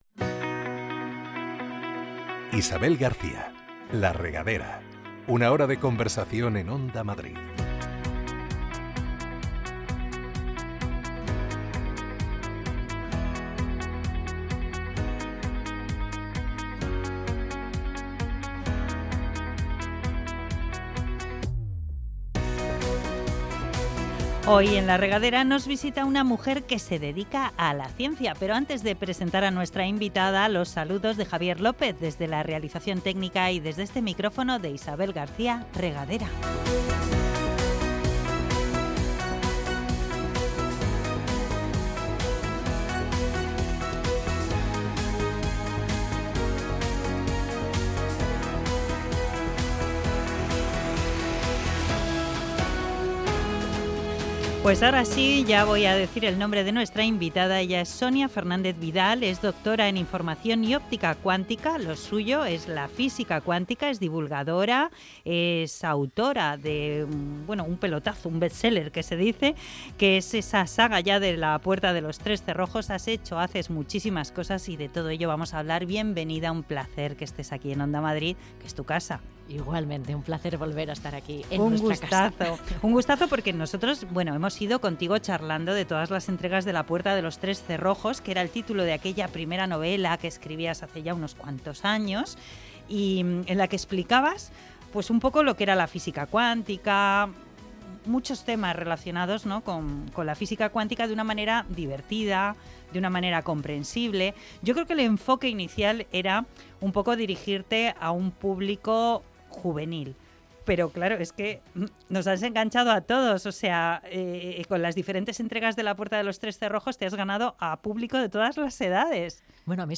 Un espacio para conversar, con buena música de fondo y conocer en profundidad a todo tipo de personajes interesantes y populares.